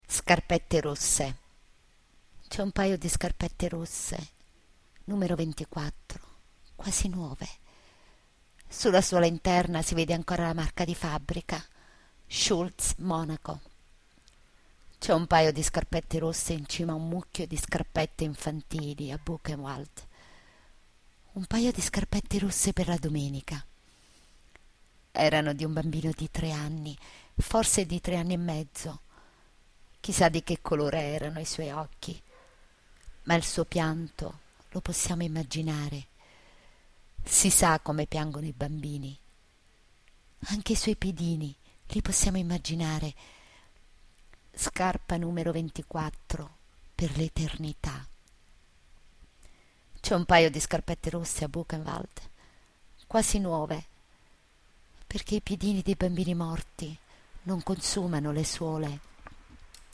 Poesie recitate da docenti